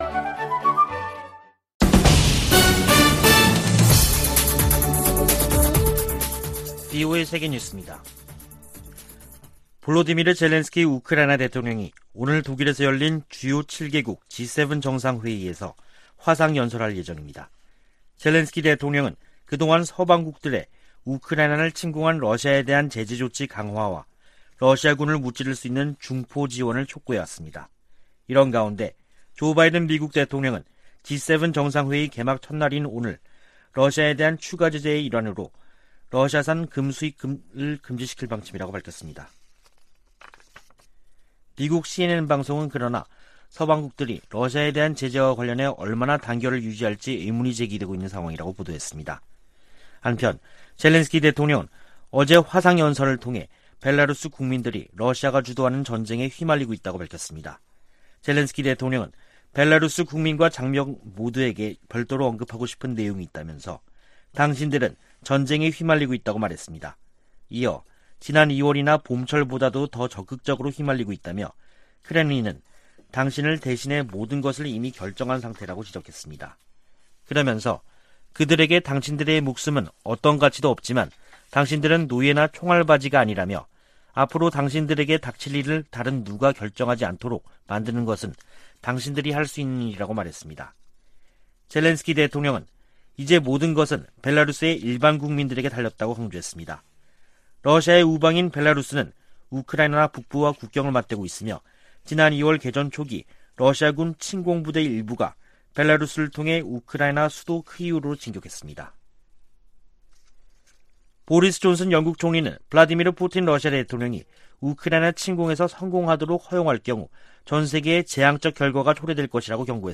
VOA 한국어 간판 뉴스 프로그램 '뉴스 투데이', 2022년 6월 27일 3부 방송입니다. 권영세 한국 통일부 장관은 북한이 7차 핵실험을 할 경우 엄청난 비판에 직면할 것이라고 경고했습니다. 미국 의회 산하 위원회가 한국의 난민정책에 관한 청문회에서 문재인 정부에 의한 탈북 어민 강제북송을 비판했습니다. 알래스카 미군 기지가 북한의 미사일 위협을 24시간 감시하고 있다고 강조했습니다.